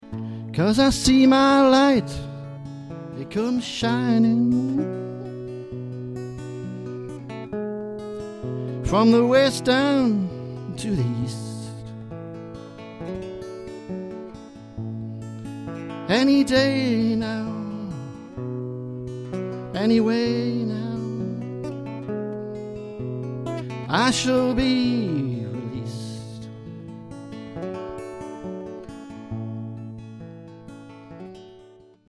Plays gentle and 'political' acoustic covers